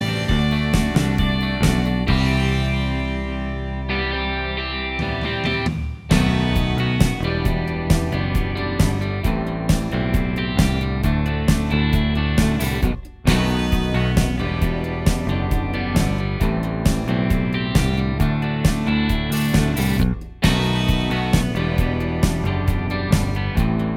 Minus Acoustic Guitar Pop (1980s) 3:23 Buy £1.50